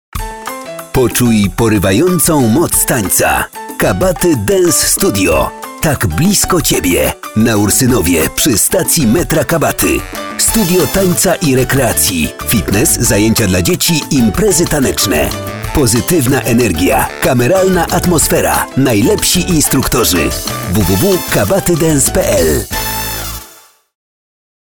Sprecher polnisch für TV / Rundfunk / Industrie.
Sprechprobe: Industrie (Muttersprache):
Professionell voice over artist from Poland.